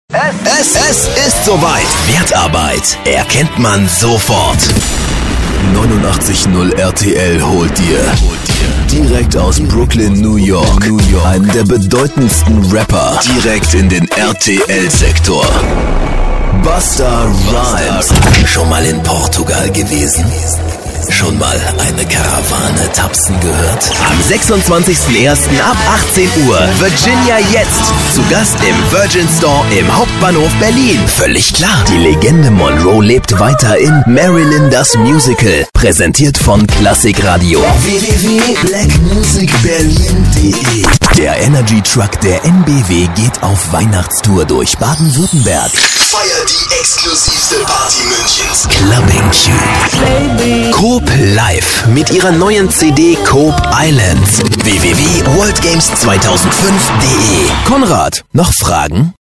Stimme mit Wiedererkennungswert, großer Flexibilität und Zuverlässigkeit. Eigenes Studio mit MusicTaxi und Aptx.
Sprecher deutsch.
Sprechprobe: Werbung (Muttersprache):
german voice over artist